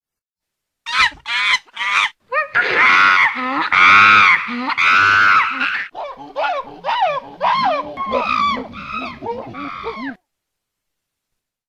Catégorie Animaux